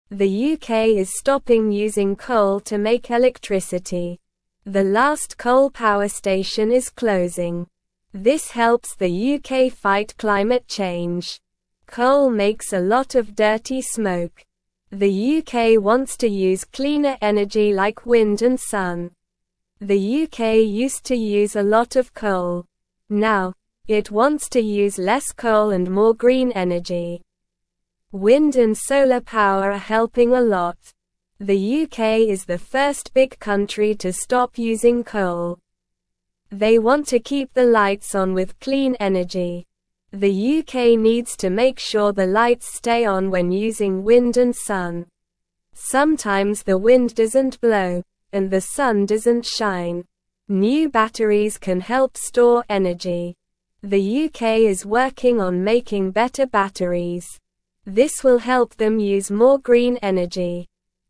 Slow
English-Newsroom-Beginner-SLOW-Reading-UK-Stops-Using-Coal-for-Electricity-Fights-Climate-Change.mp3